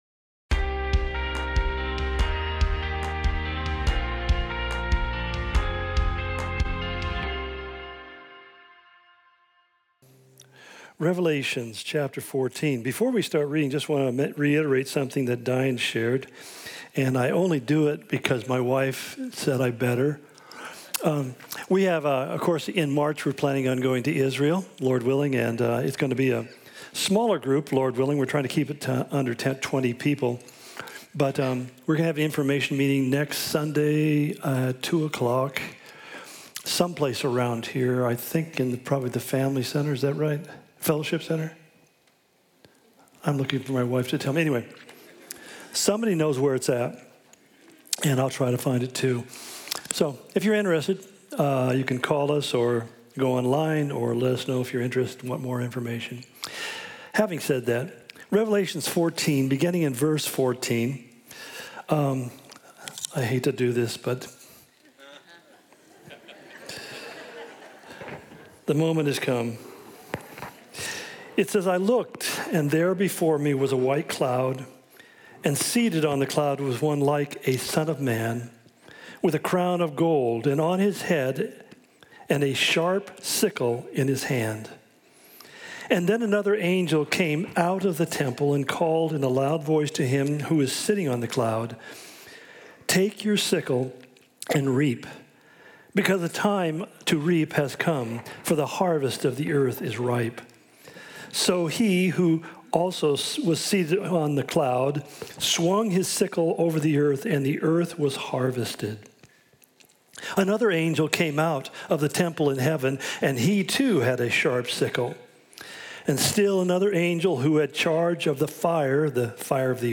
Calvary Spokane Sermon Of The Week podcast